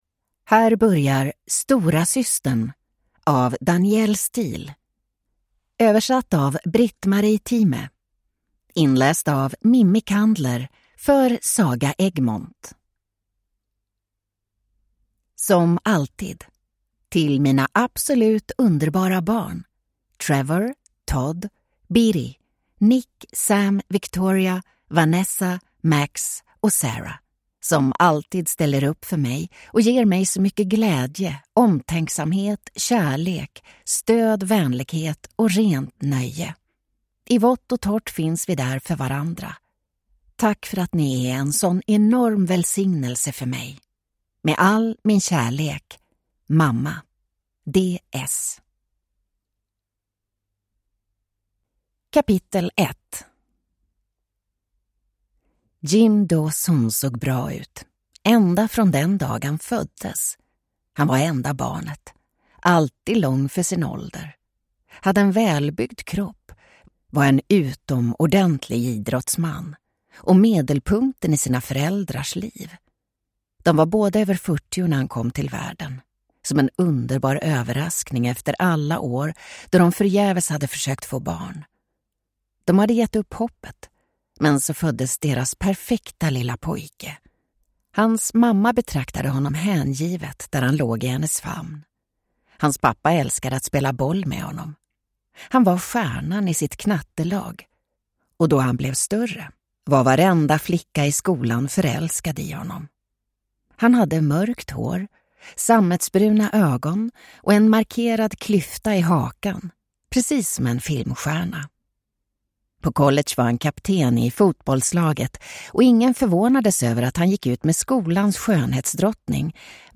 Storasystern – Ljudbok